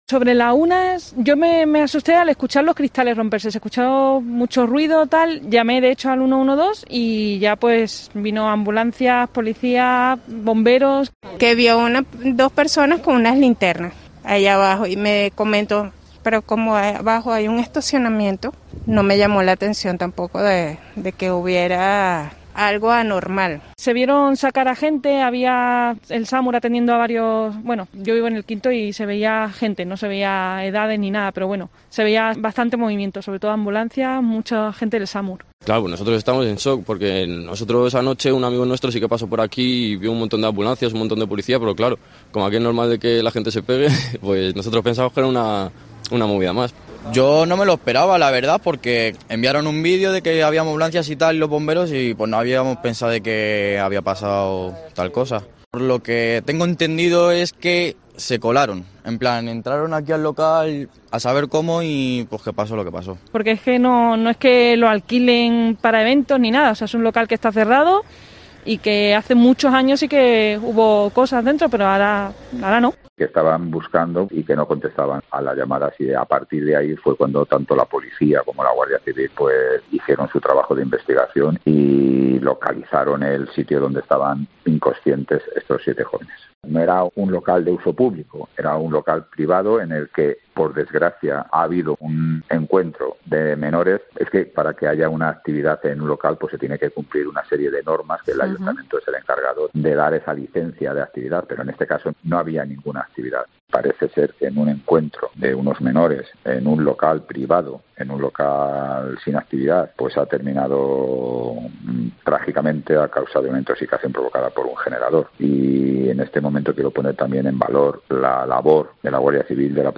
(Escucha las declaraciones que adjuntamos a esta información de vecinos y vecinas de Azuqueca de Henares, así como del alcalde de la localidad, José Luis Blanco, tras el trágico suceso que ha consternado a su municipio y a toda la provincia de Guadalajara)